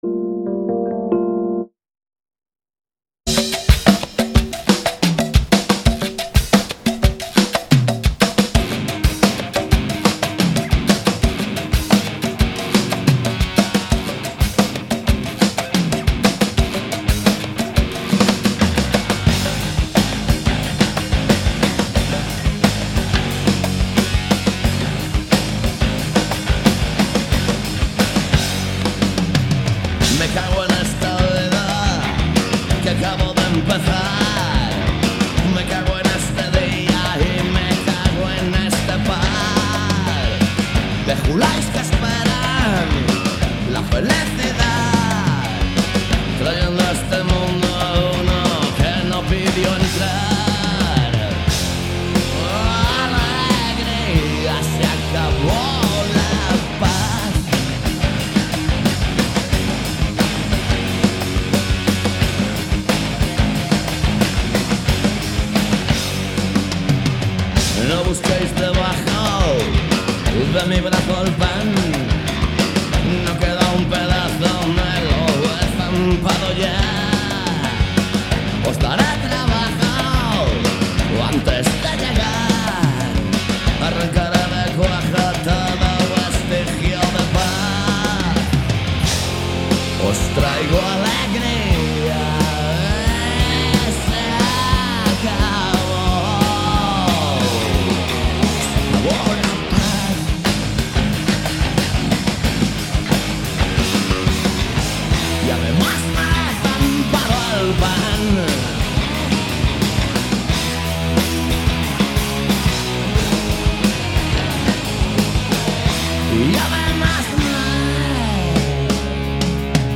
Todo iso misturado con boa música e un pouco de humor se o tema o permite. Cada martes ás 18 horas en directo.
Alegría comezou a emitirse en outubro de 2003 e dende entón non falla nas ondas de CUAC FM.